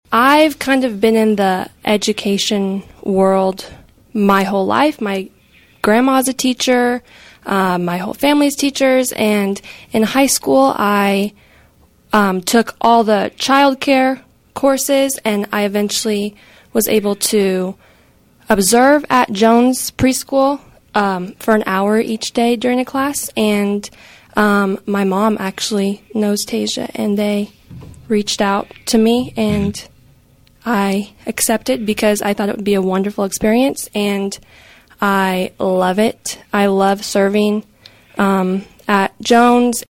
This is AmeriCorps week, and KVOE’s Morning Show had several guests from Emporia State University’s Teachers College to discuss the program and their involvement.